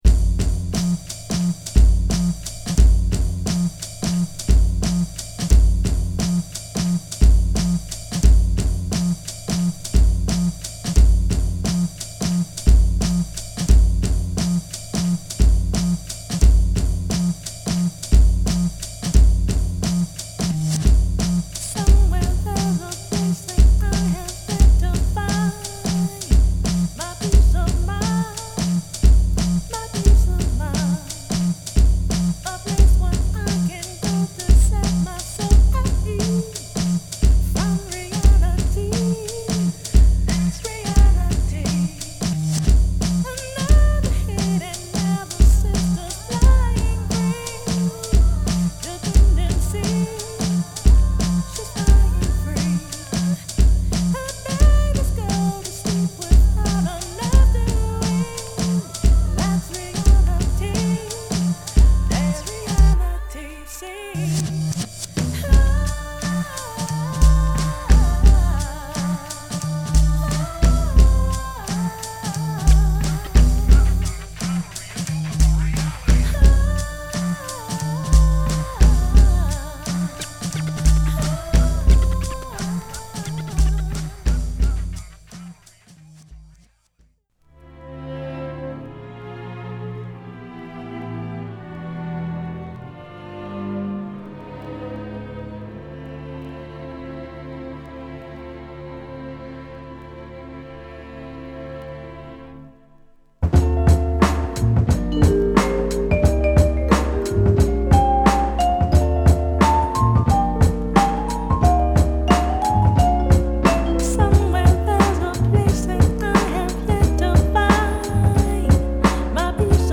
Neo Soul